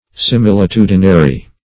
Search Result for " similitudinary" : The Collaborative International Dictionary of English v.0.48: Similitudinary \Si*mil`i*tu"di*na*ry\, a. Involving or expressing similitude.